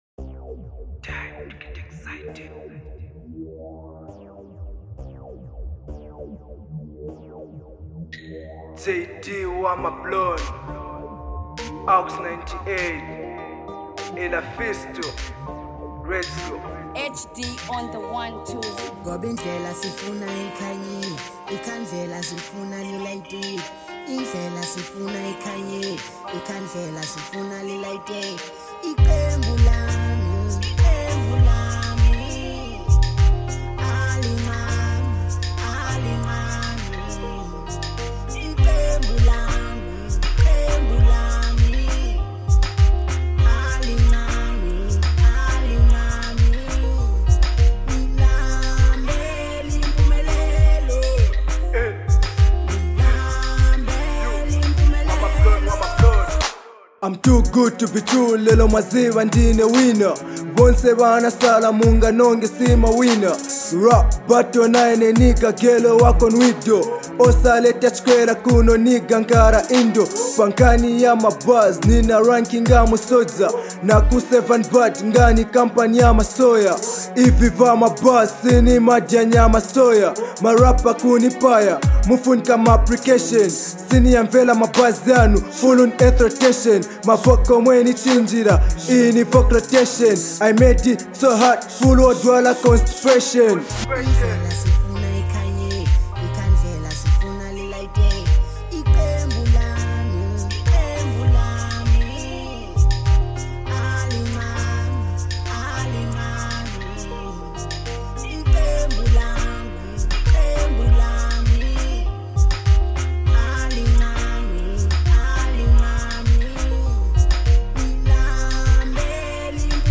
02:59 Genre : Venrap Size